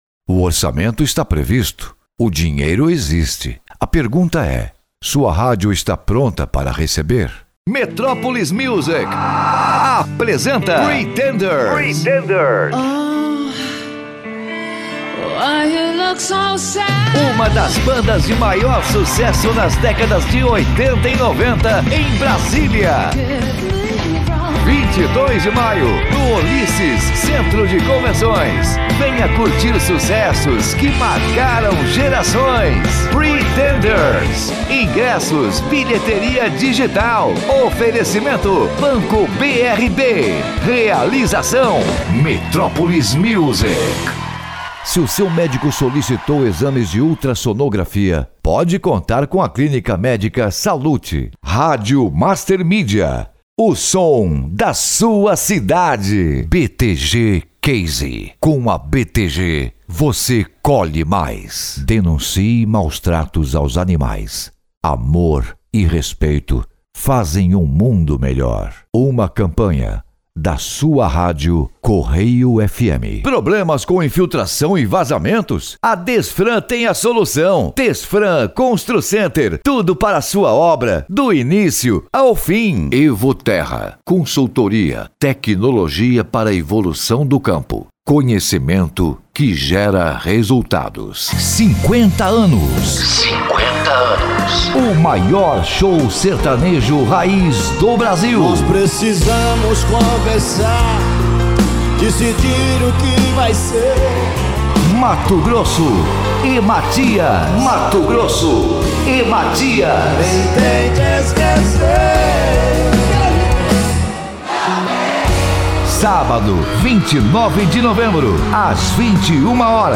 Padrão
Impacto
Animada